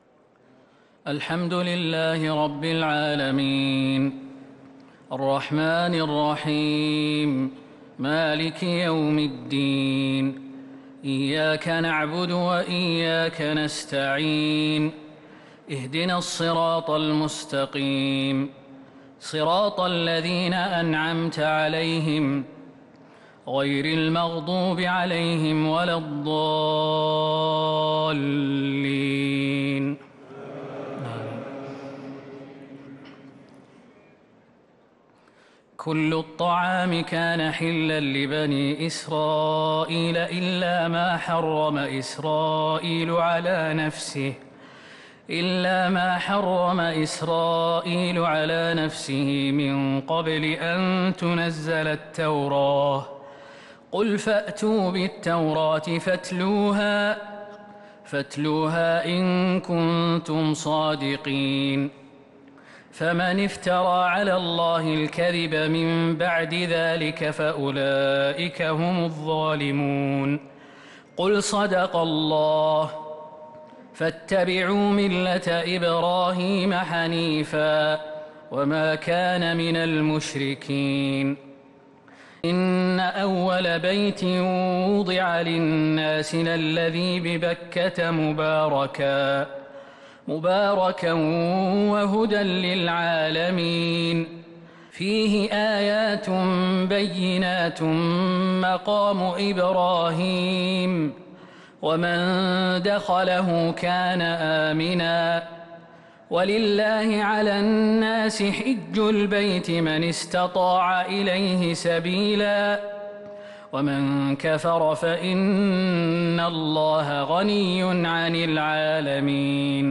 تراويح ليلة 5 رمضان 1443هـ سورة آل عمران (93-158) | Taraweeh 5th night Ramadan 1443H Surah Aal-i-Imraan > تراويح الحرم النبوي عام 1443 🕌 > التراويح - تلاوات الحرمين